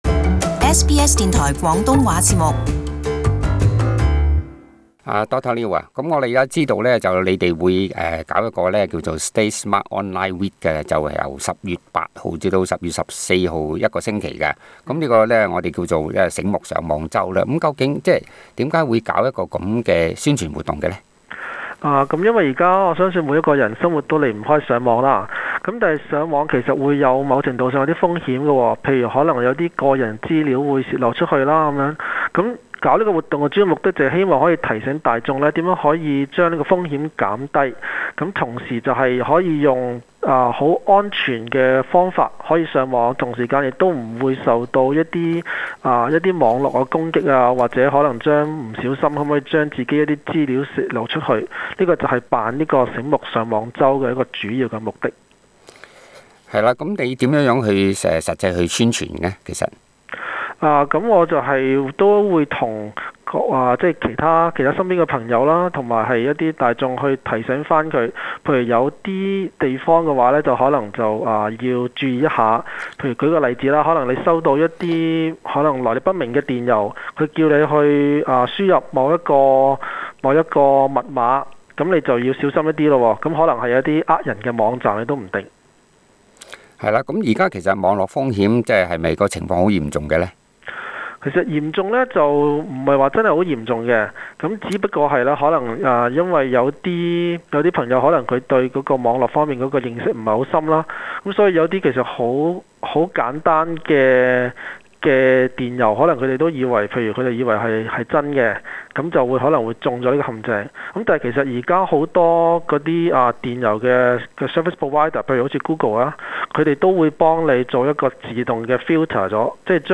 【社區專訪】如何「醒目上網」？